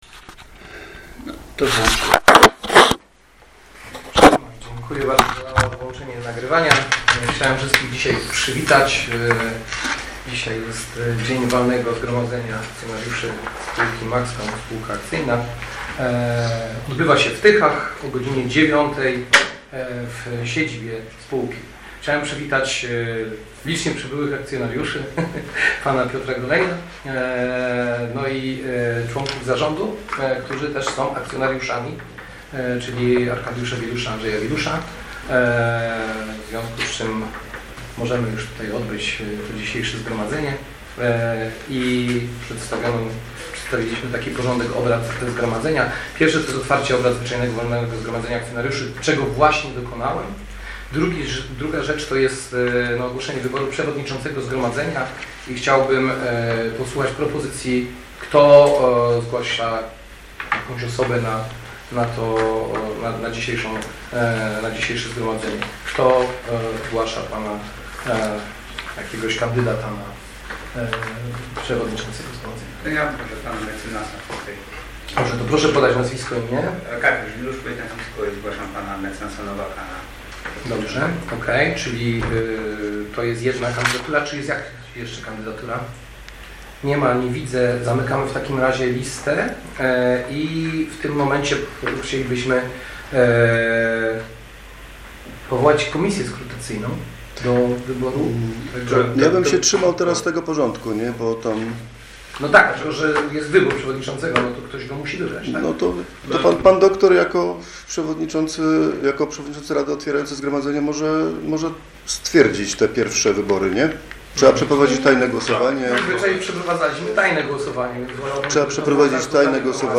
Walne Zgromadzenie Akcjonariuszy - Maxcom S.A.